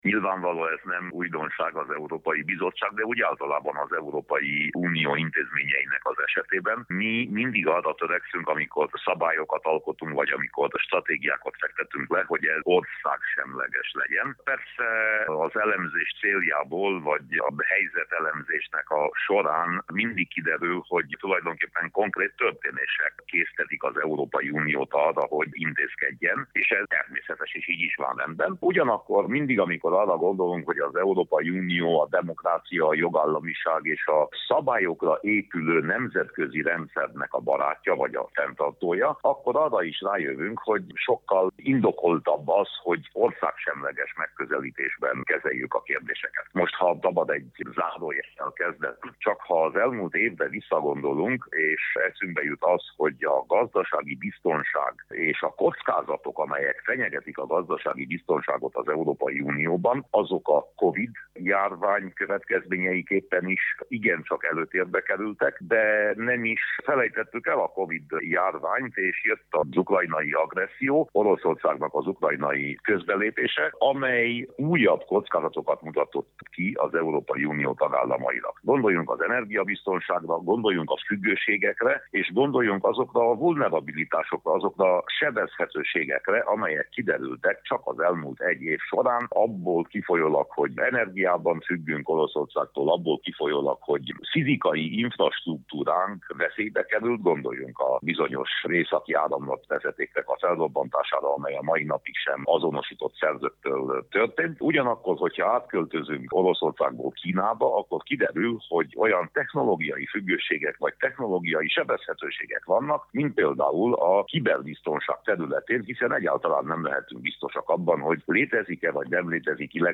A gazdasági biztonság stratégia kapcsán Winkler Gyula európai parlamenti képviselő nyilatkozott rádiónknak.